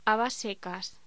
Locución: Habas secas
voz